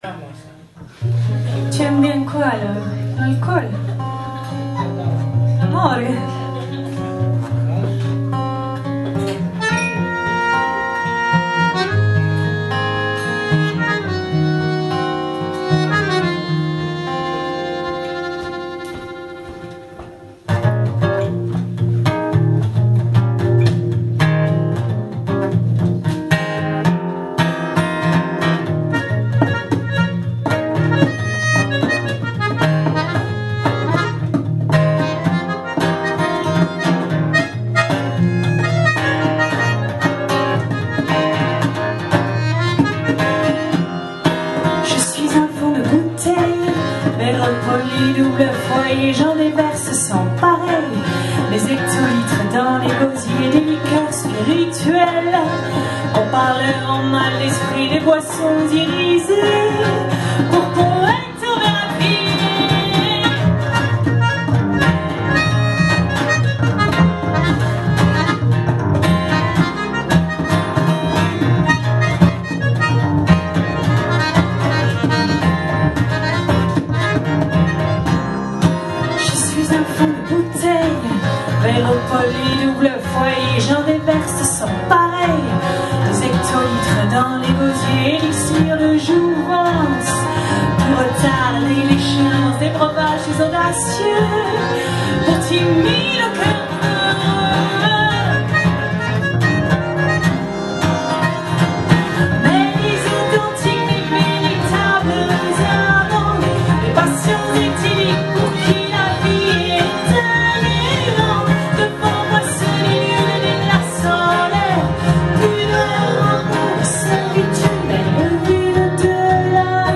accordéon
violon
contrebasse